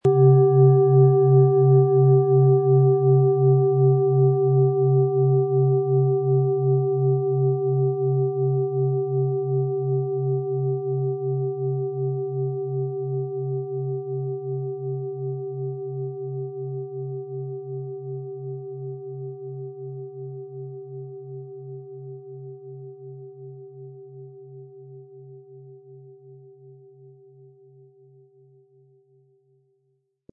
Wie klingt diese tibetische Klangschale mit dem Planetenton Biorhythmus Körper?
MaterialBronze